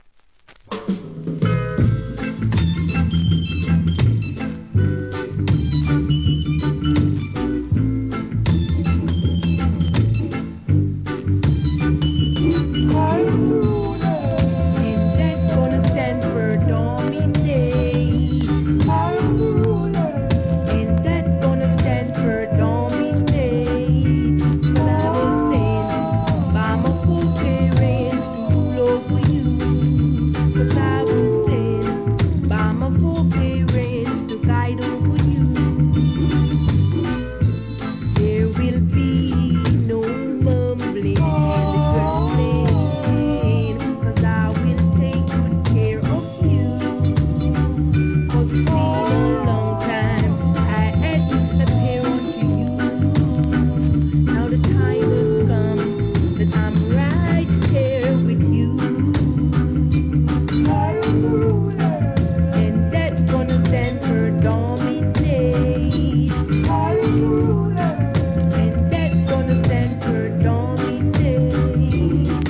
REGGAE
'60s、KILLER ROCKSTEADY!!!